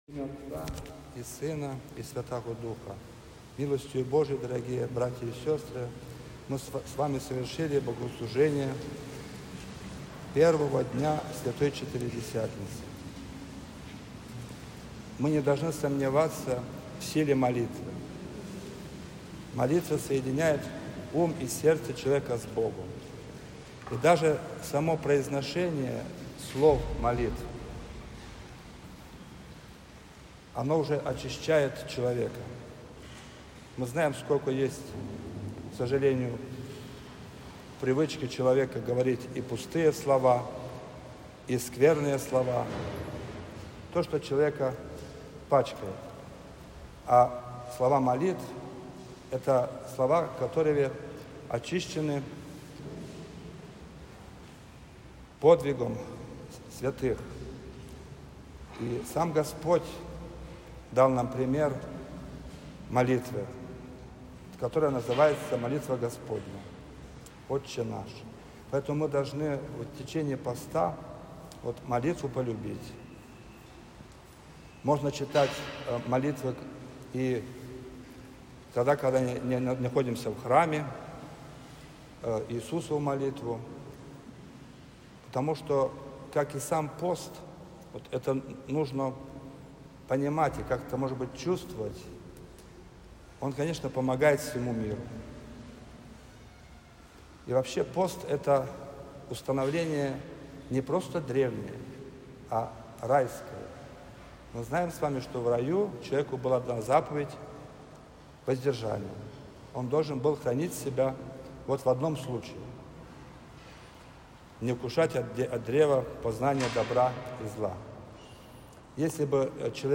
23 февраля 2026 года, в понедельник 1-й седмицы Великого поста было совершено великое повечерие с чтением Великого покаянного канона преподобного Андрея Критского.
Проповедь